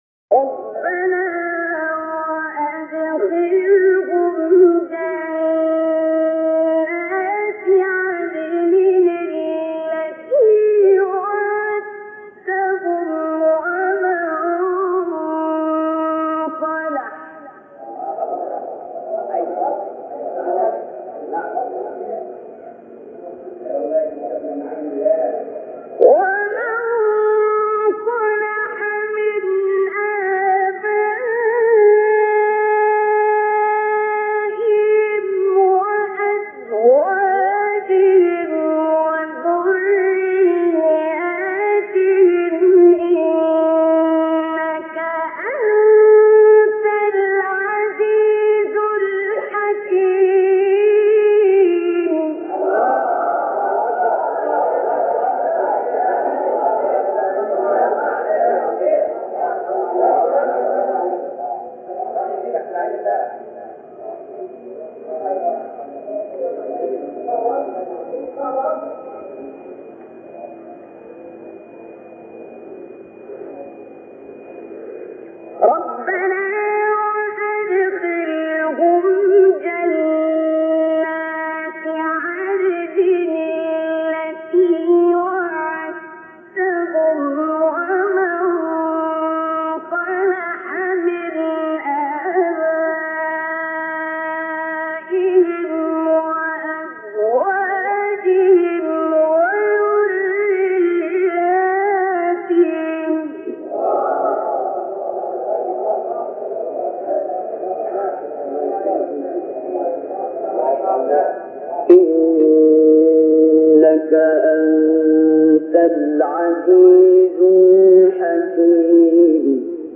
سوره : غافر آیه: 8-9 استاد : عبدالباسط محمد عبدالصمد مقام : بیات قبلی بعدی